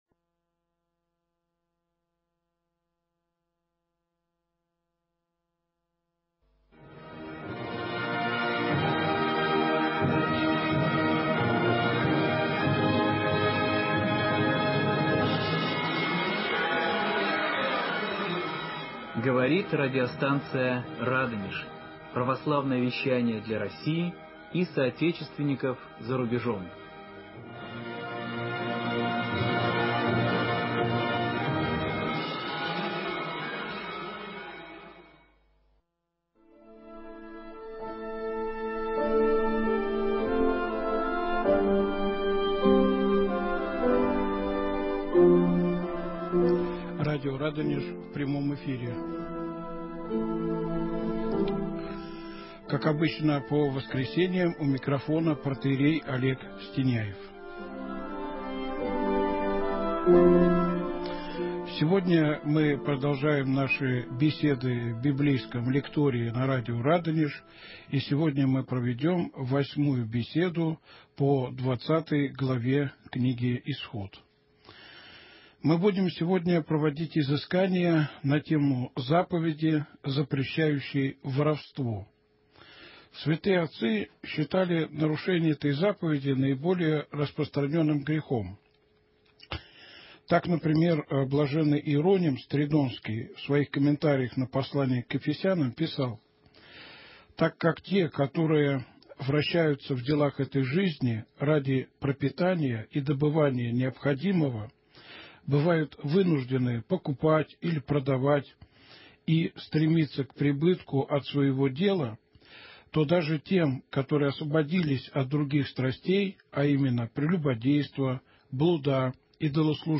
читает и комментирует книгу Исход (Ветхий Завет)